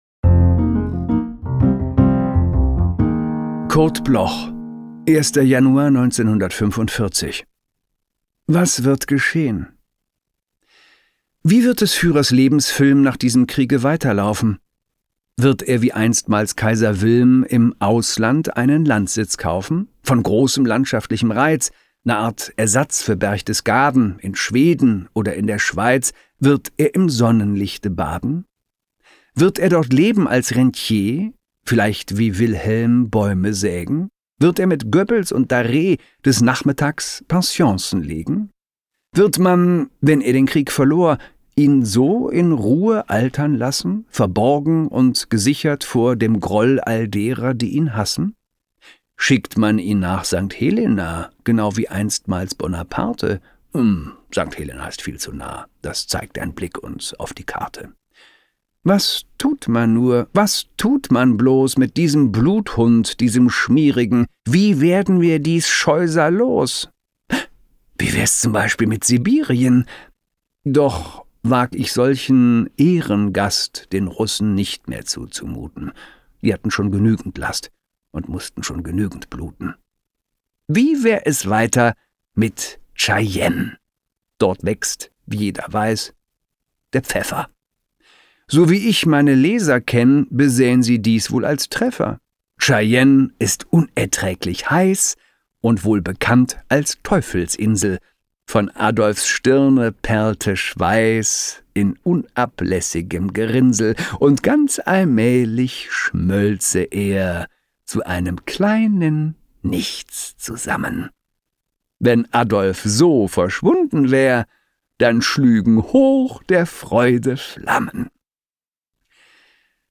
performed by Christoph Maria Herbst
Christoph-Maria-Herbst-Was-wird-geschehn-mit-Musik.mp3